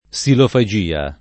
vai all'elenco alfabetico delle voci ingrandisci il carattere 100% rimpicciolisci il carattere stampa invia tramite posta elettronica codividi su Facebook silofagia [ S ilofa J& a ] o xilofagia [ k S ilofa J& a ] s. f. (zool.)